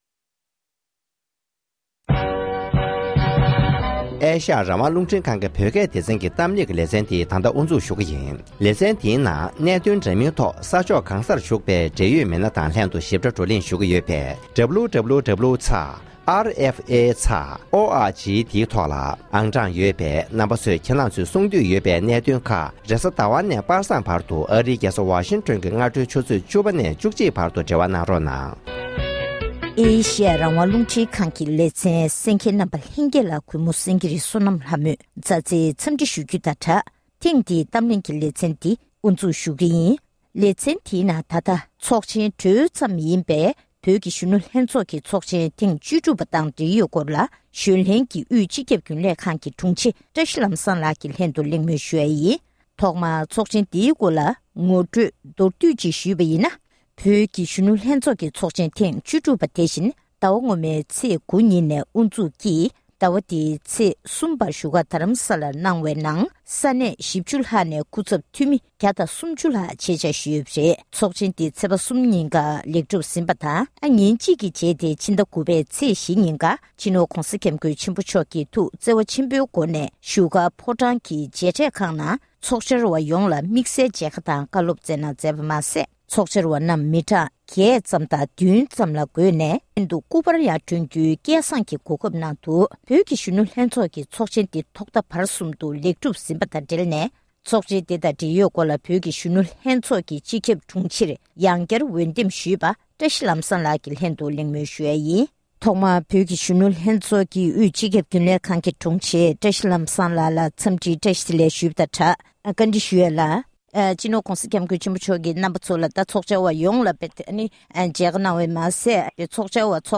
གླེང་མོལ་ཞུས་པ་ཞིག་གསན་རོགས་གནང༎